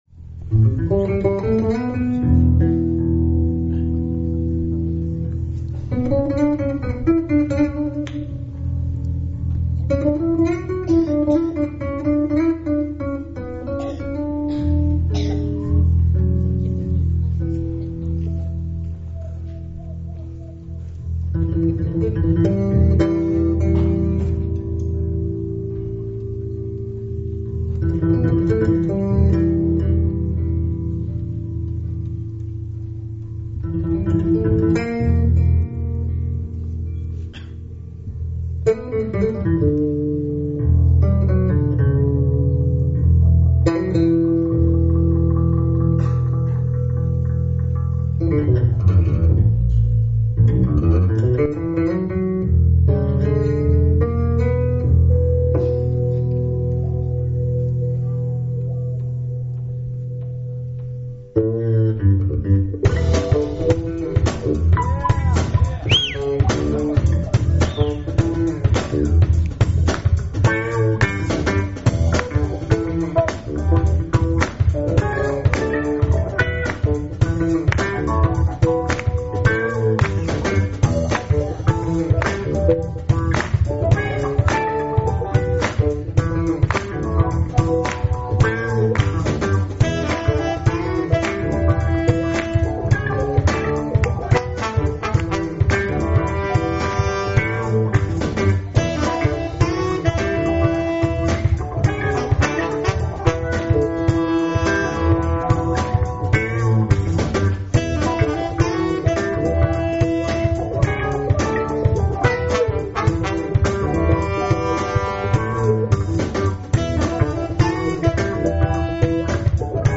jazz/funk